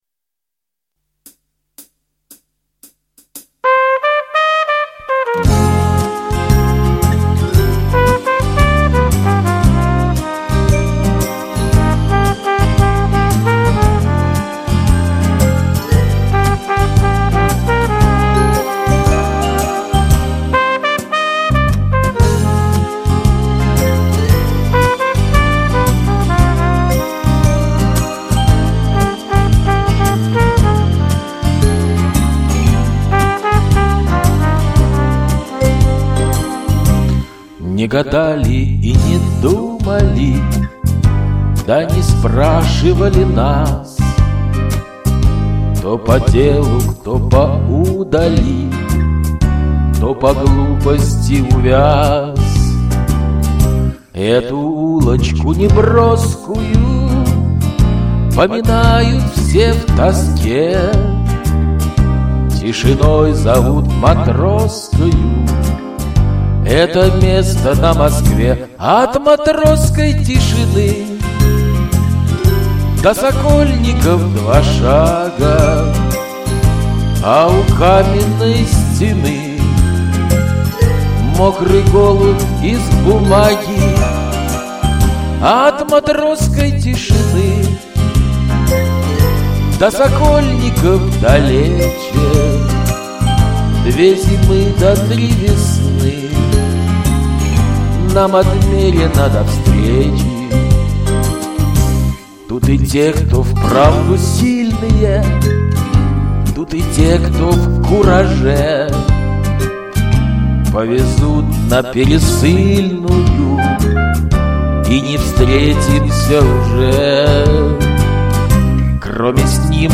более романтиШная што ли получилась